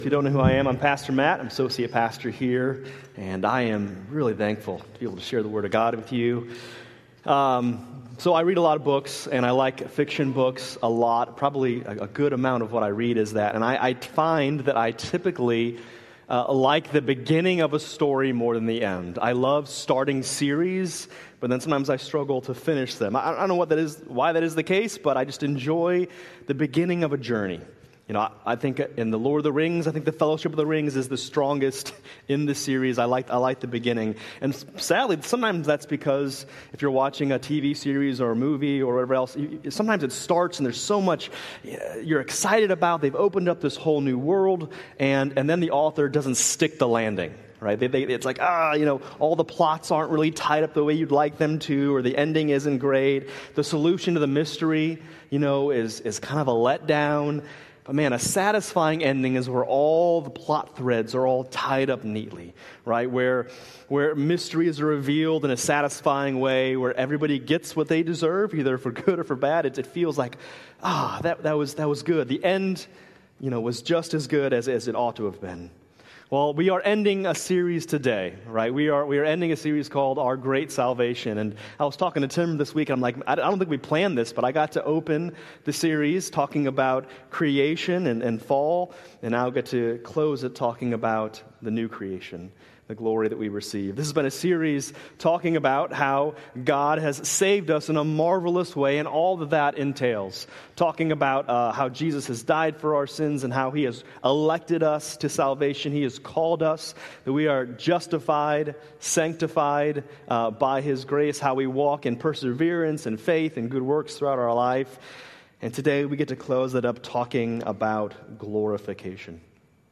August 24, 2025 Worship Service Order of Service: